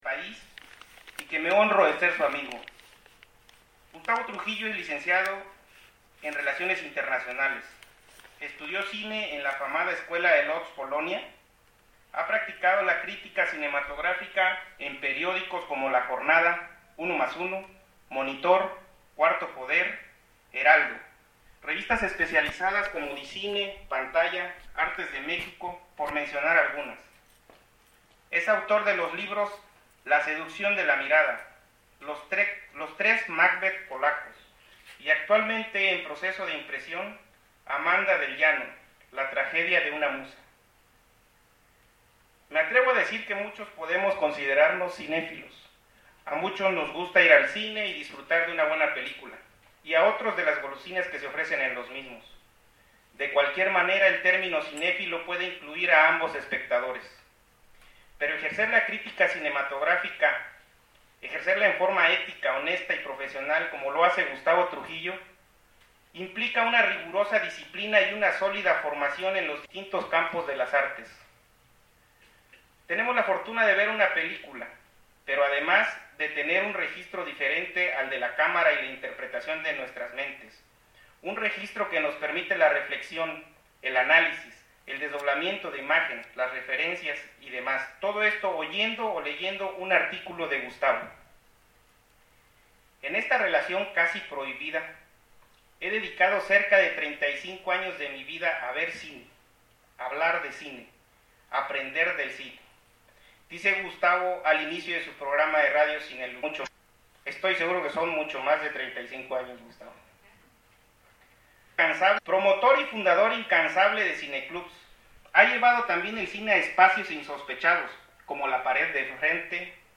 Les invitamos a disfrutar de esta charla con la cual seguramente escucharemos y apreciaremos el septimo arte en distinta forma.
Lugar: Sala de Cine de la Escuela de Cine Descartes
Micrófono: Binaurales